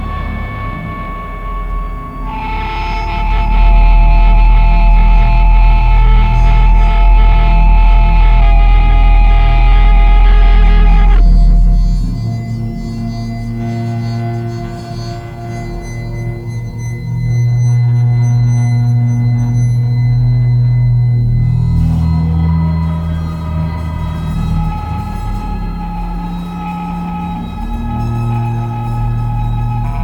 électronique
batt, harpe basse